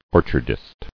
[or·char·dist]